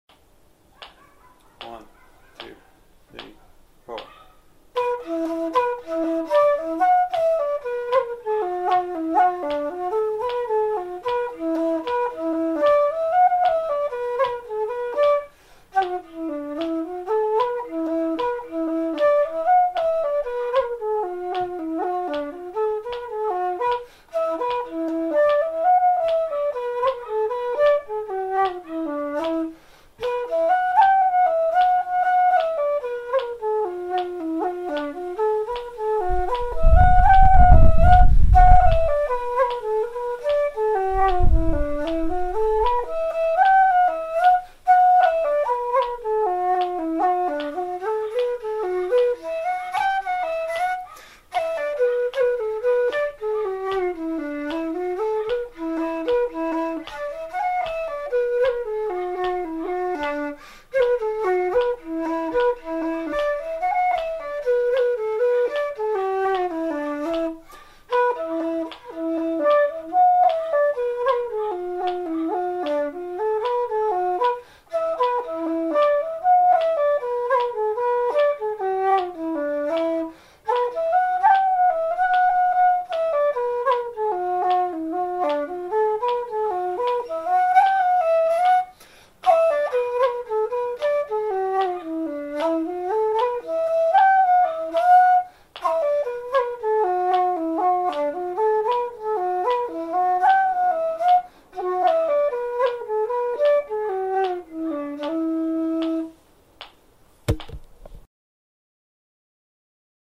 killavil-slow.mp3